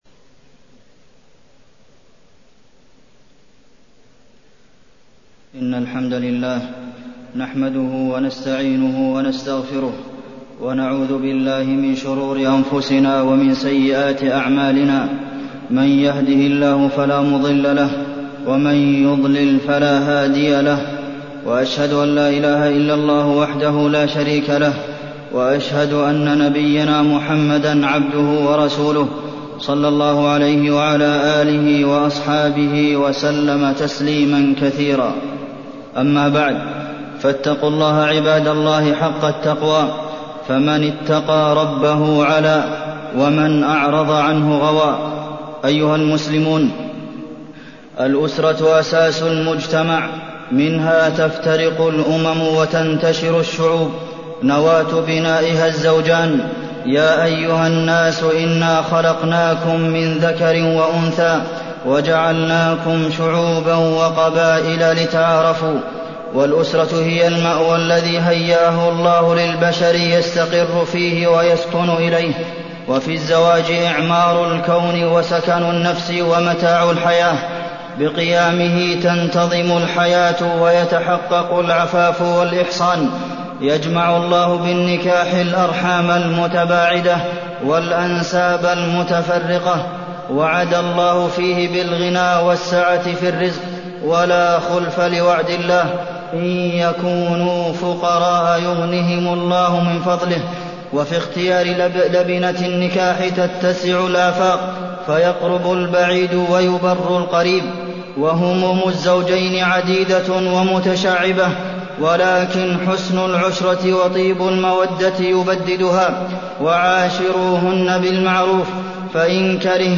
تاريخ النشر ٩ جمادى الأولى ١٤٢٣ هـ المكان: المسجد النبوي الشيخ: فضيلة الشيخ د. عبدالمحسن بن محمد القاسم فضيلة الشيخ د. عبدالمحسن بن محمد القاسم حقوق الزوجين